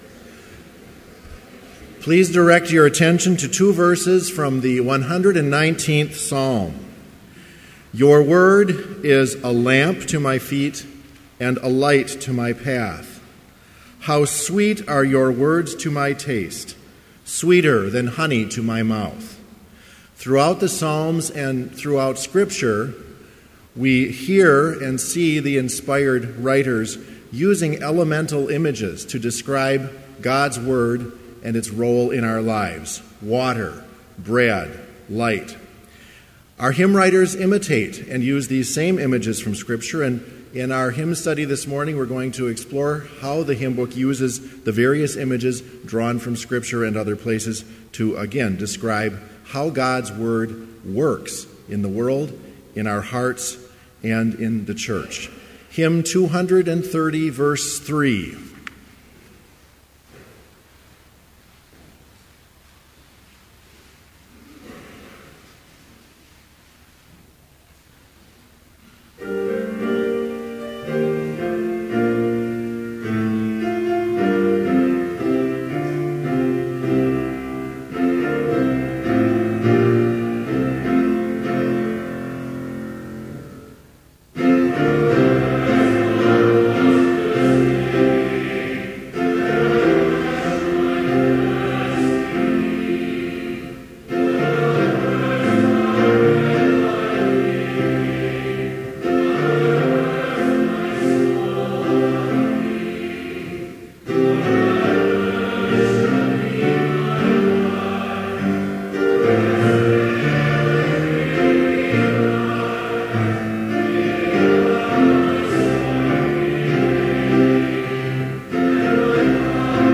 Chapel worship service in BLC's Trinity Chapel, February 10, 2015, (audio available) with None Specified preaching.
Complete service audio for Chapel - February 10, 2015